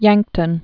(yăngktən)